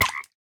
minecraft / sounds / mob / frog / hurt4.ogg
hurt4.ogg